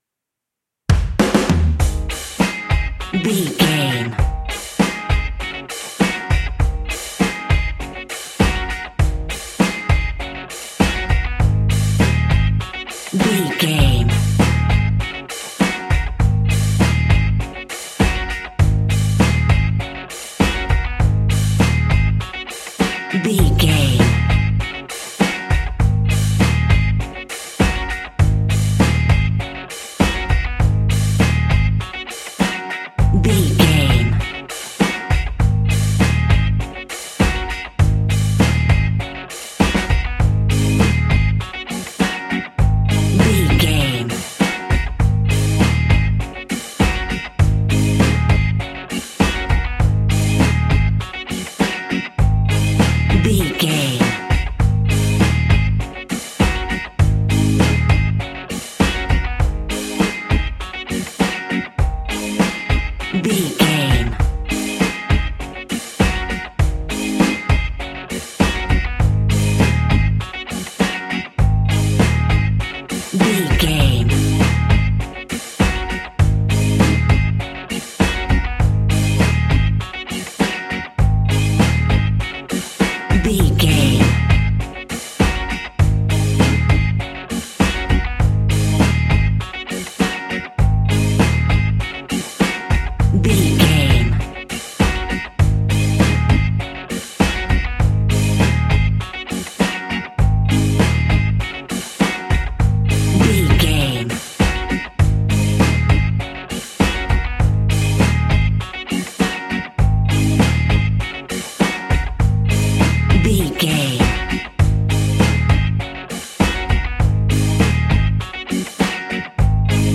Classic reggae music with that skank bounce reggae feeling.
Uplifting
Ionian/Major
A♭
laid back
off beat
drums
skank guitar
hammond organ
horns